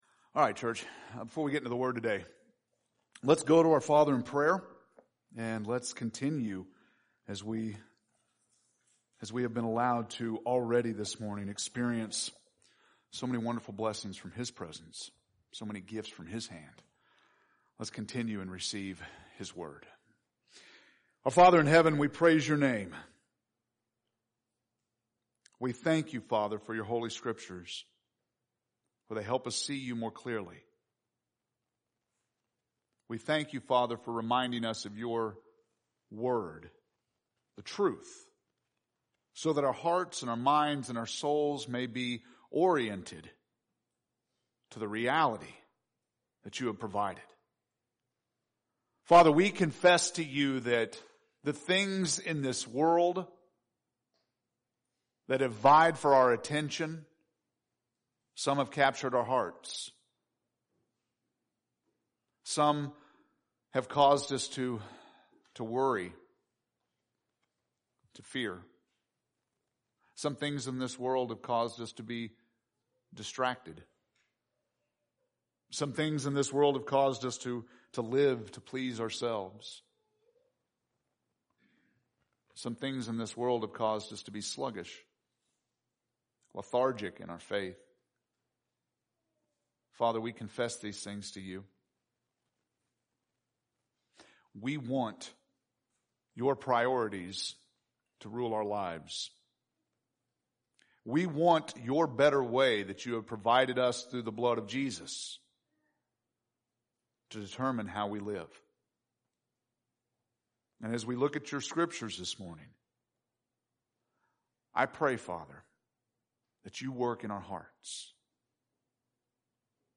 October 4th – Sermons